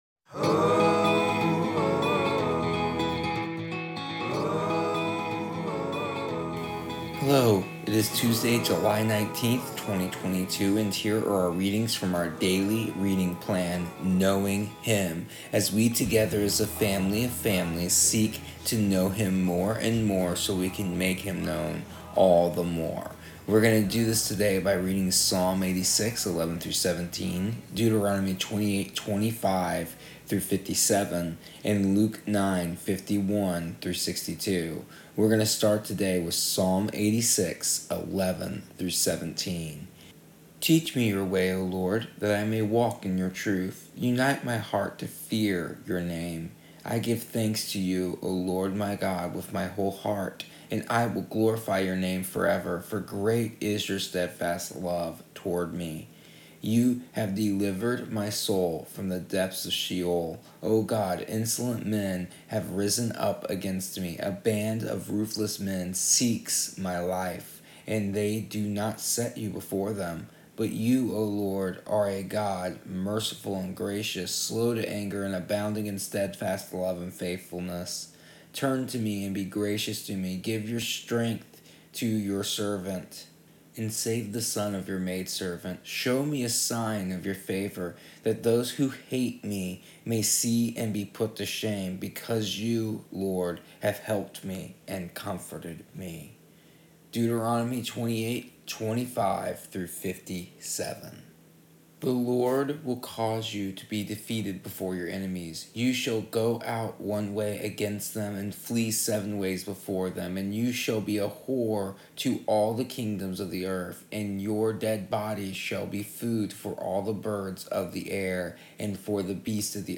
daily readings